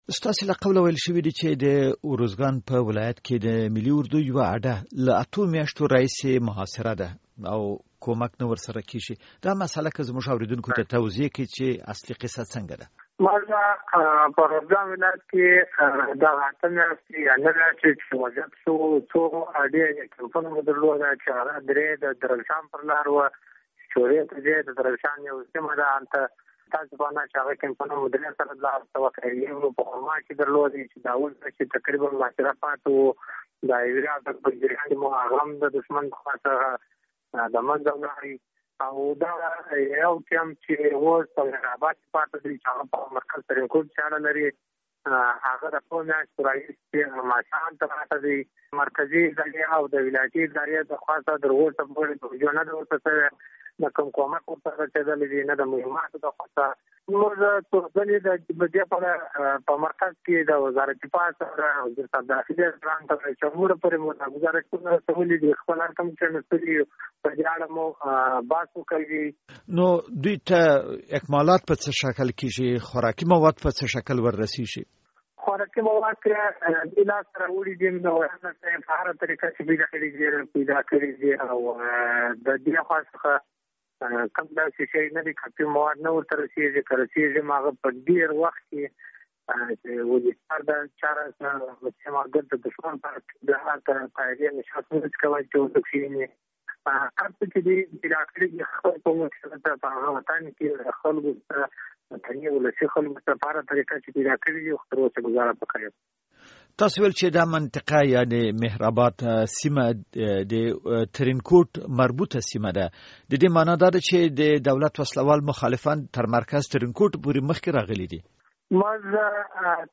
مرکه
له امیر محمد بارکزي سره مرکه.